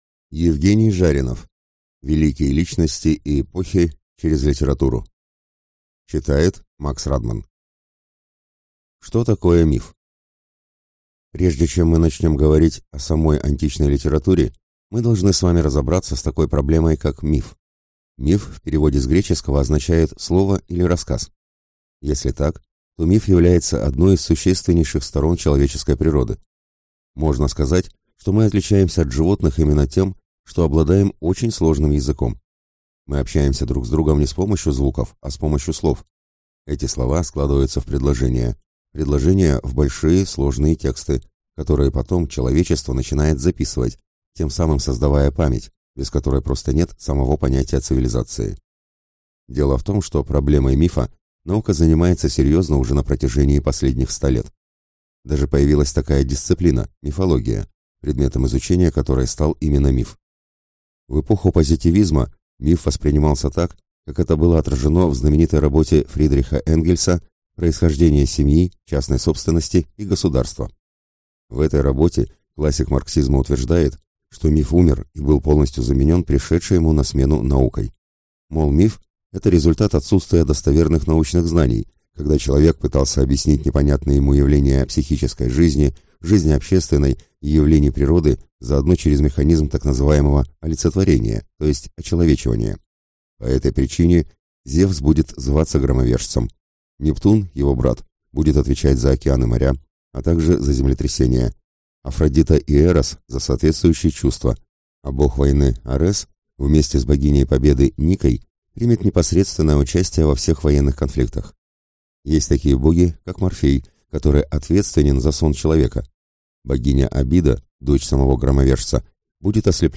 Аудиокнига Великие личности и эпохи через литературу | Библиотека аудиокниг